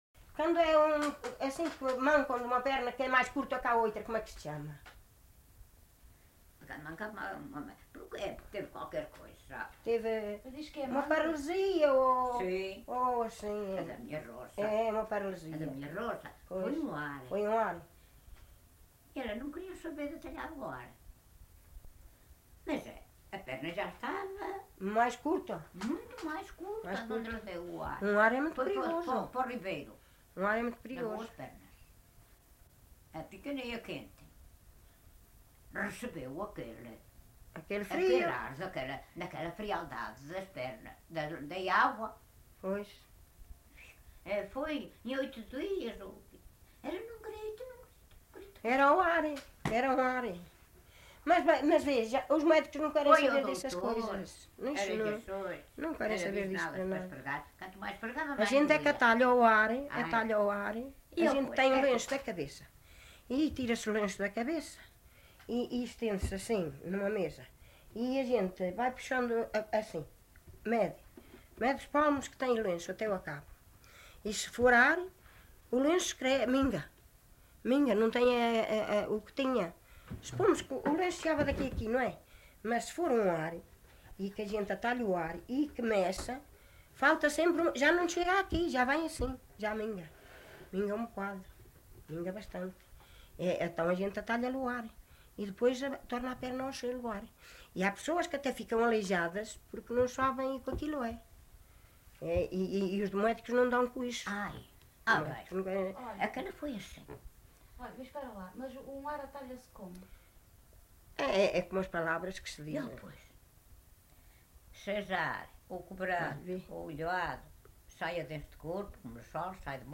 LocalidadeGranjal (Sernancelhe, Viseu)